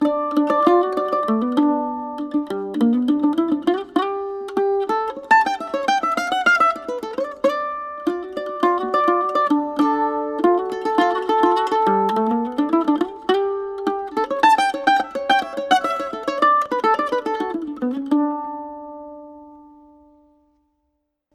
Bandolim 10 cordes Regis Bonilha
Je l’ ai montée avec des filets plats, le son est plus moelleux……
bandolim2.mp3